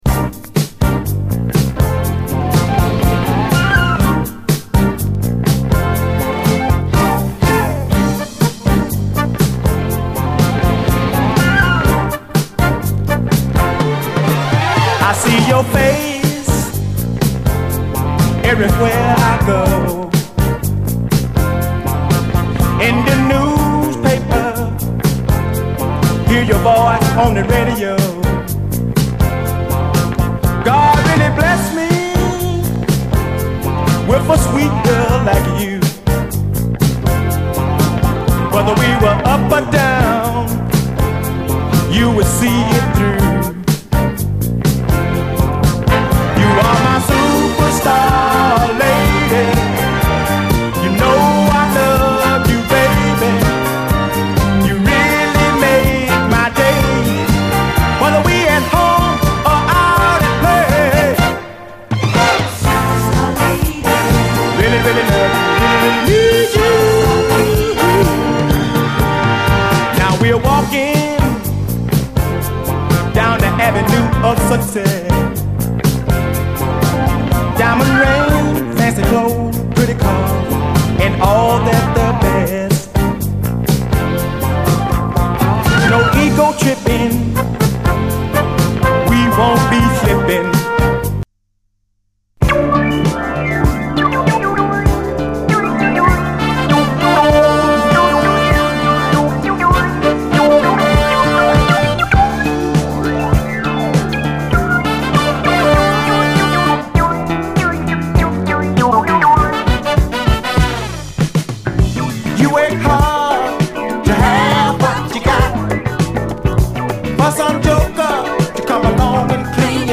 様々なディスコ・ヒットの弾き直しトラックによるB級カヴァーやディスコ・ラップ群が嬉しい！
オールドスクール・ディスコ・ラップの持つB級感を愛するアナタに！